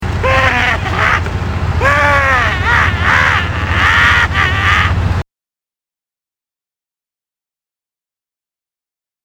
Laughing